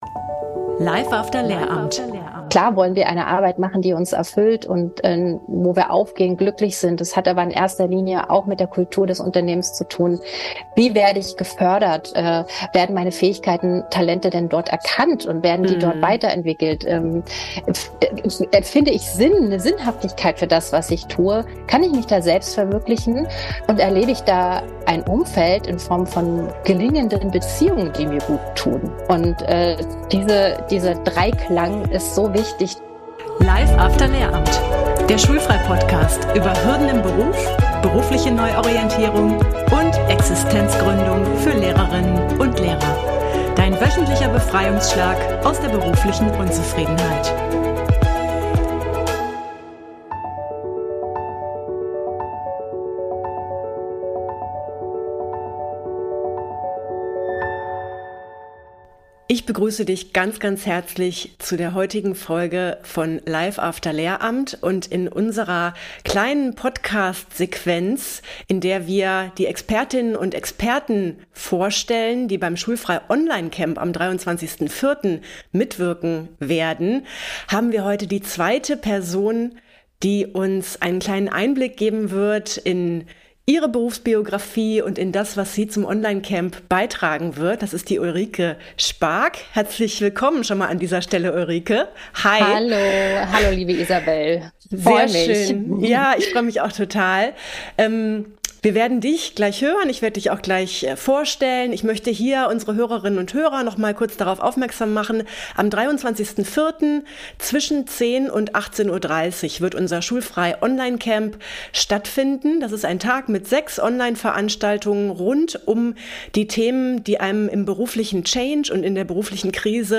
#55 Die Positive Psychologie als Navi zum Jobglück nutzen. Interview